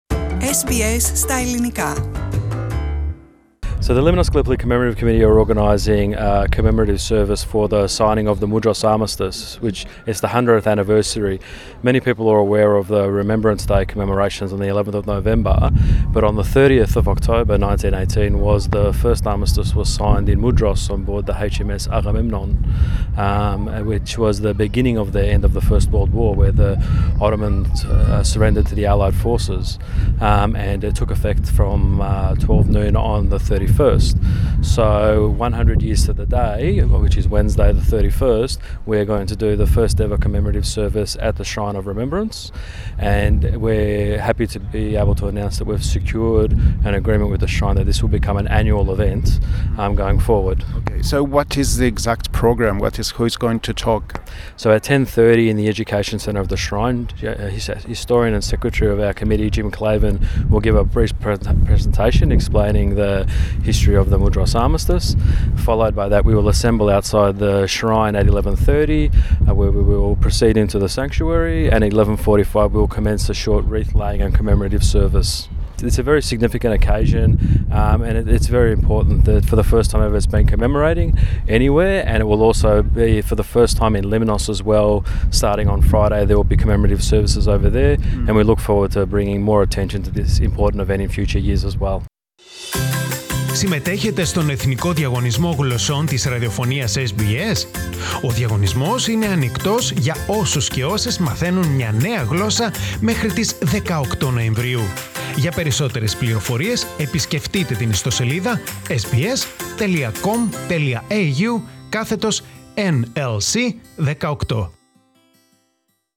The Lemnos Gallipoli Commemorative Committee is organizing a commemorative service for the signing of the Mudros Armistice which it's the hundredth anniversary. Lee Tarlamis OAM, president of the Melbourne-based committee, talks to SBS Greek